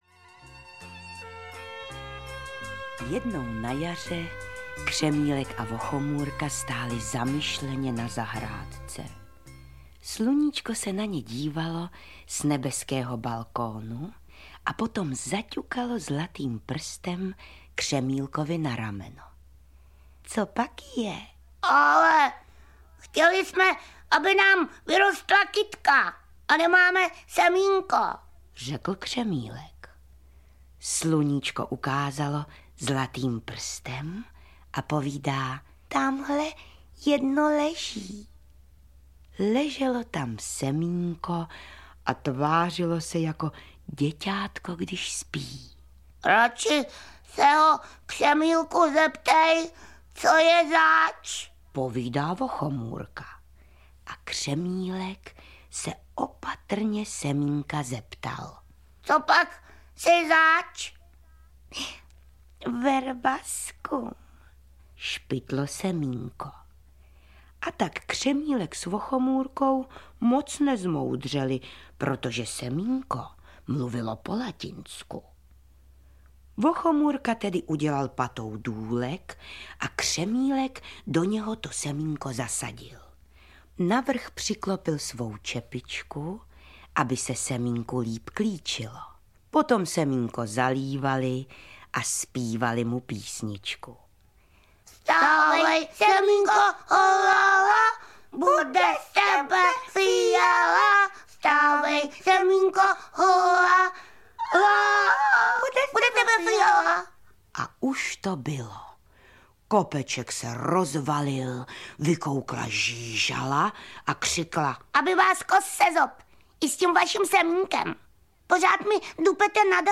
Výběr snad nejznámějších večerníčkových příběhů vyprávěných Jiřinou Bohdalovou.
Audio kniha
Ukázka z knihy
• InterpretJiřina Bohdalová